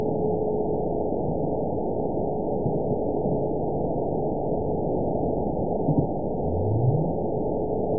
event 920297 date 03/13/24 time 03:12:15 GMT (1 year, 1 month ago) score 9.56 location TSS-AB02 detected by nrw target species NRW annotations +NRW Spectrogram: Frequency (kHz) vs. Time (s) audio not available .wav